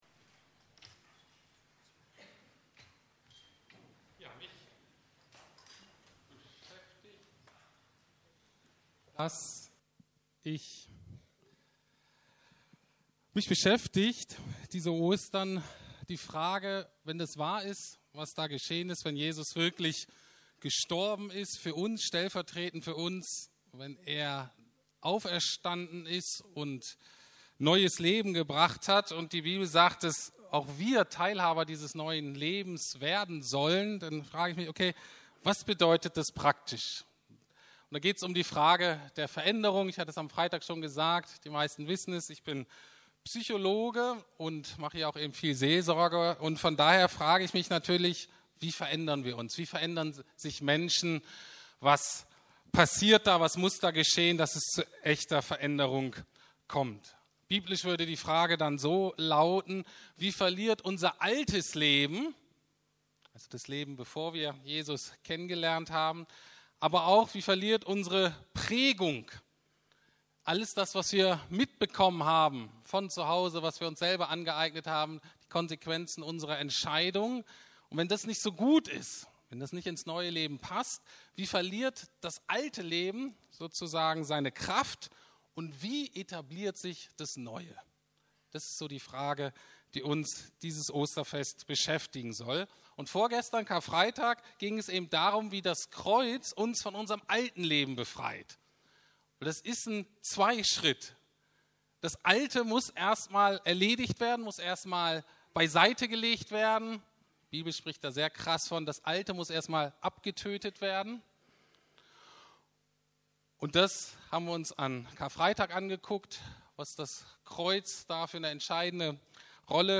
Christus ist auferstanden, neues Leben steht in uns auf ~ Predigten der LUKAS GEMEINDE Podcast